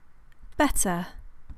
以下はイギリス英語は「T」をはっきり発音する例です。上品な印象の英語に聞こえませんか？